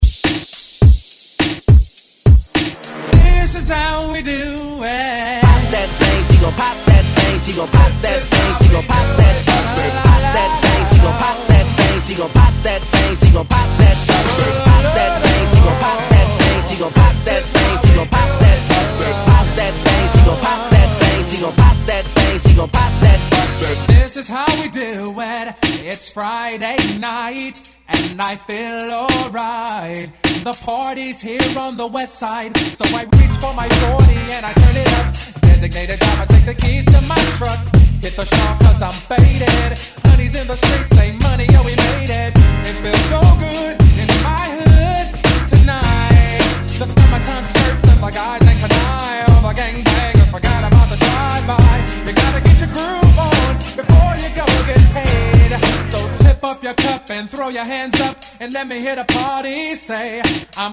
Genre: TOP40 Version: Clean BPM: 124 Time